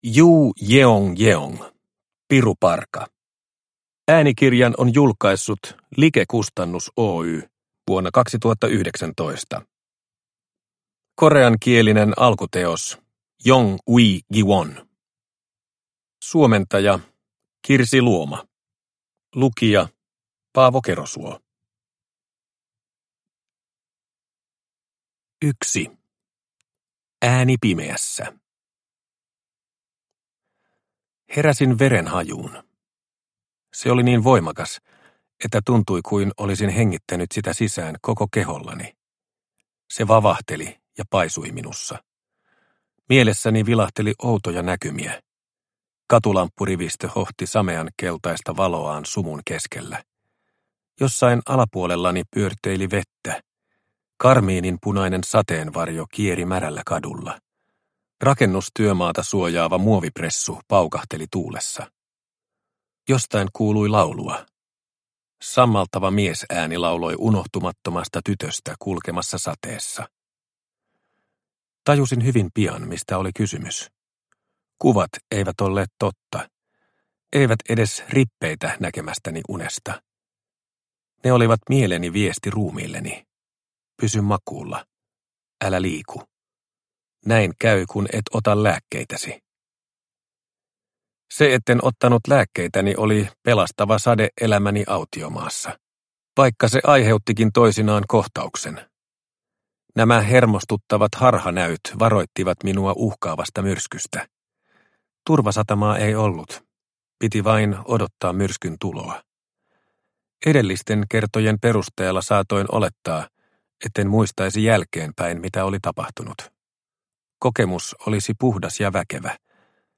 Piruparka – Ljudbok – Laddas ner